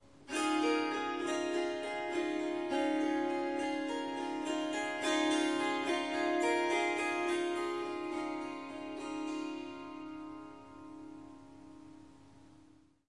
Swarmandal印度竖琴曲谱 " 竖琴弹拨与弹奏10
这个奇妙的乐器是Swarmandal和Tampura的结合。 15个竖琴弦和4个Drone / Bass琴弦。
它被调到C sharp，但我已经将第四个音符（F sharp）从音阶中删除了。
这些片段取自三天不同的录音，因此您可能会发现音量和背景噪音略有差异。一些录音有一些环境噪音（鸟鸣，风铃）。
声道立体声